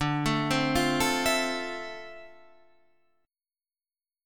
Dm9 chord